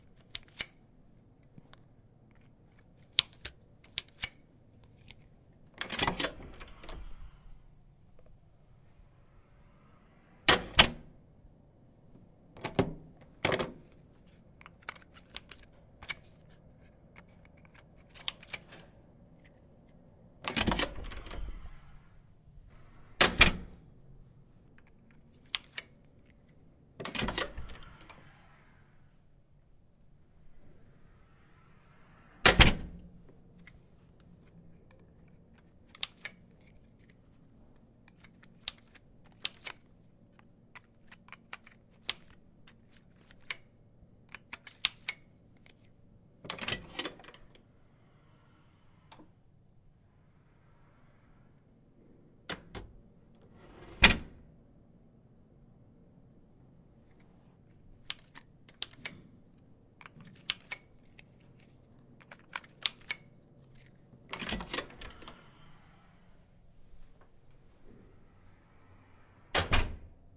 随机的 "门 "的金属装置推杆与安全钥匙卡锁打开关闭
描述：门金属instutional推杆与安全钥匙卡锁打开close.flac
Tag: 开放 安全 酒吧 instutional FLAC 金属 锁定 关闭 门卡